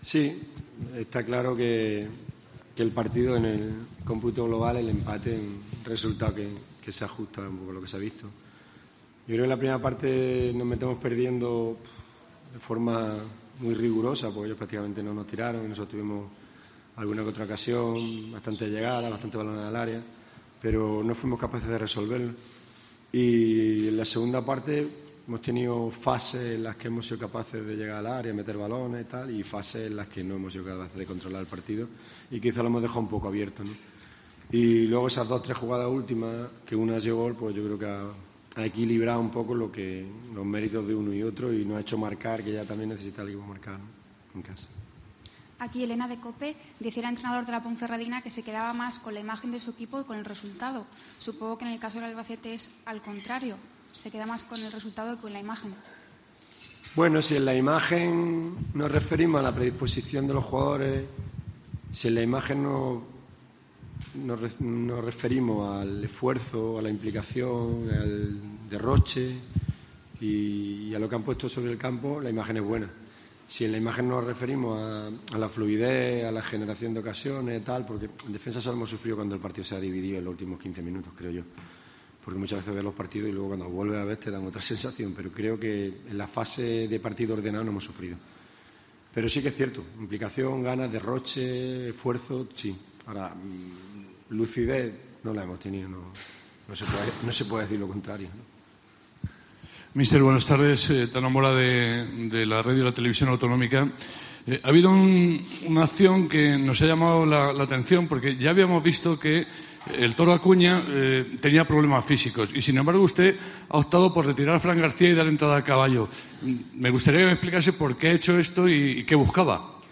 AUDIO: Escucha aquí las palabras del entrenador del Albacete, Lucas Alcaraz, tras el empate 1-1 ante la Deportiva Ponferradina